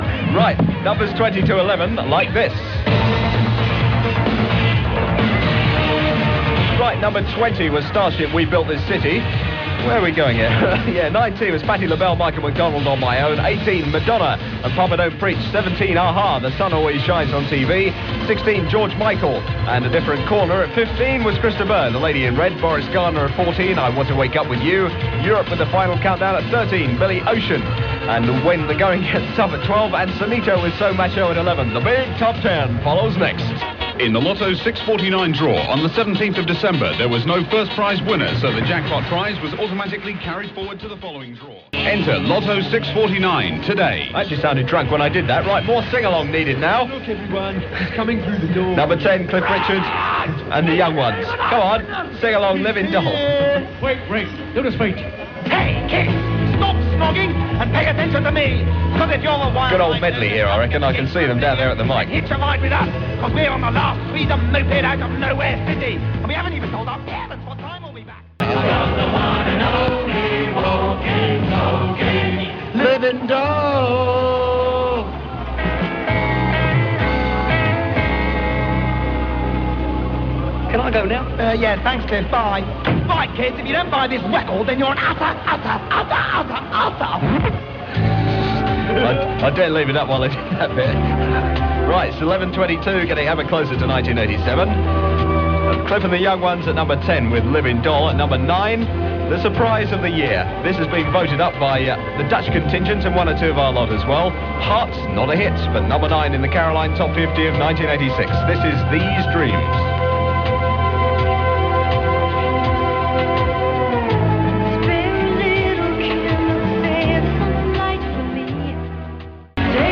co-hosted a programme of their favourite releases of the year